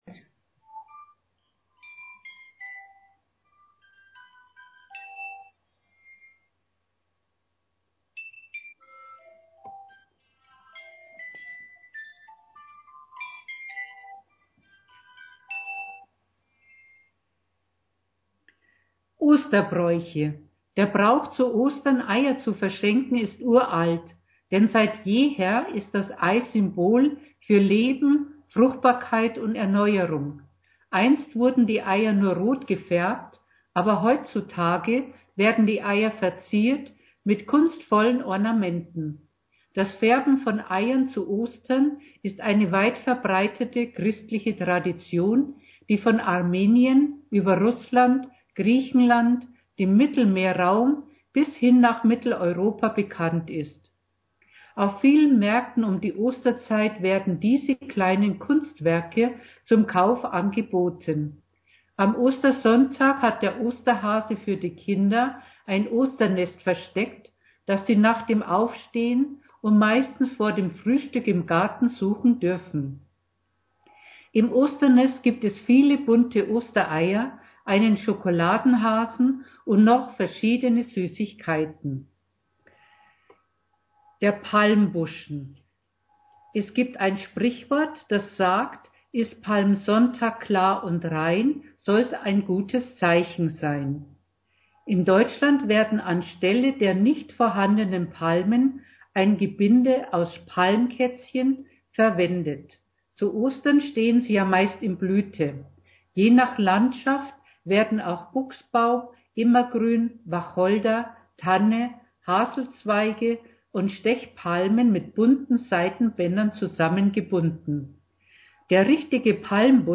Interview about Easter tradition
Osterbraeuche_German.mp3